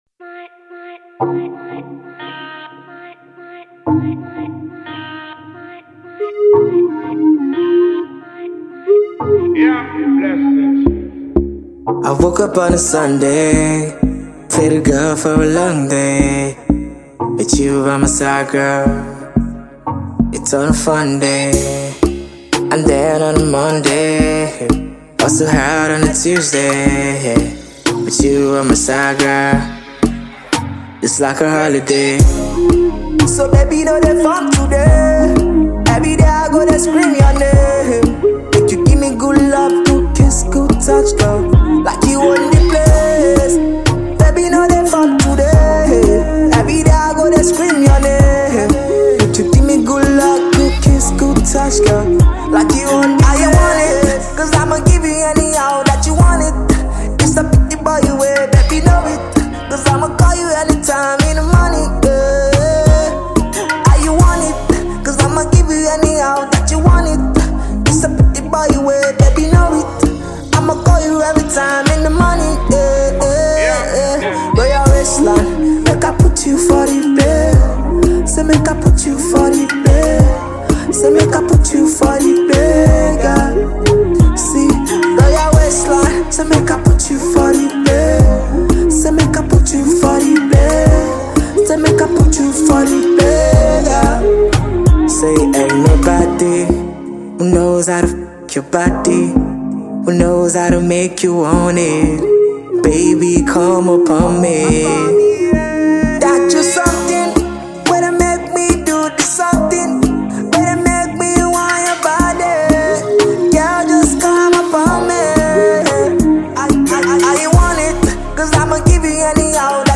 an enchanting blend of Afro R&B and Afrobeat music